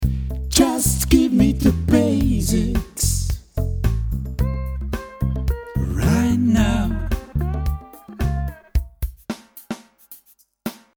Endlich ist er da: Der Klingelton zu Ihrem Lieblingsmodul!